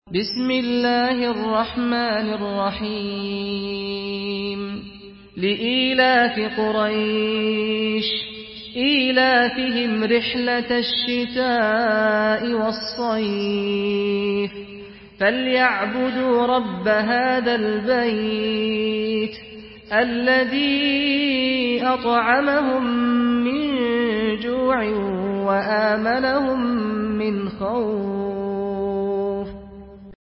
سورة قريش MP3 بصوت سعد الغامدي برواية حفص
مرتل حفص عن عاصم